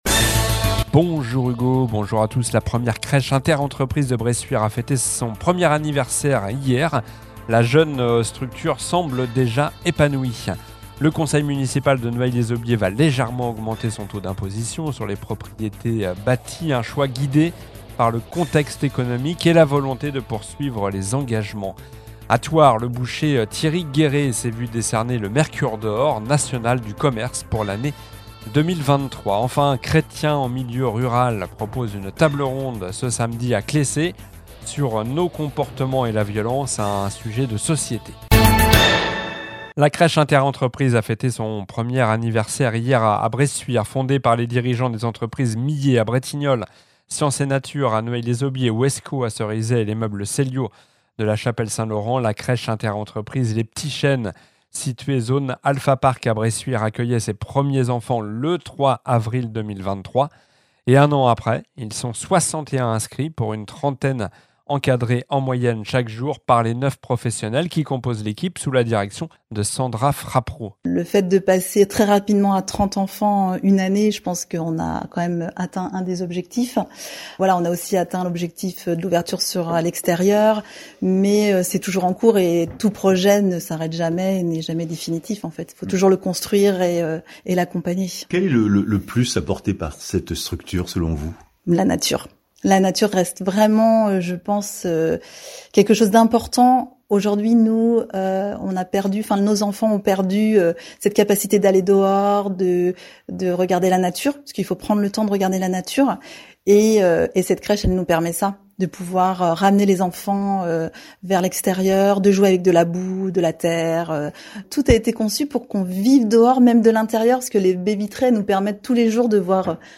Journal du jeudi 4 avril (midi)